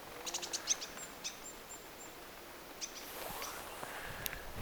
Äänite: onko sinitiainen
vaiko hömötiainen?
ilmeisesti sinitiaislintu?
ilm_sinitiaislintu.mp3